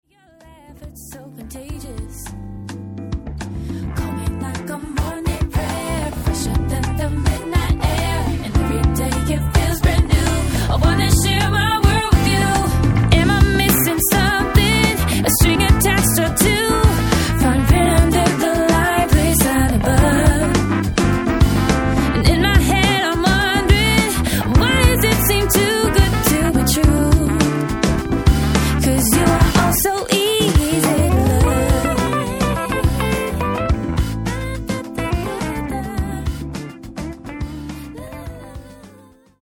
FREE SOUL